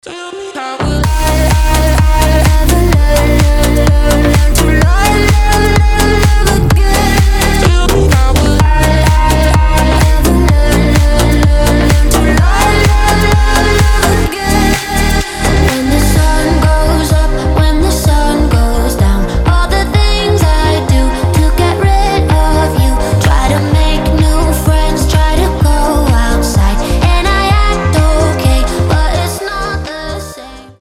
• Качество: 320, Stereo
громкие
EDM
future house
красивый женский голос
slap house